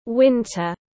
Mùa đông tiếng anh gọi là winter, phiên âm tiếng anh đọc là /ˈwɪn.tər/
Winter /ˈwɪn.tər/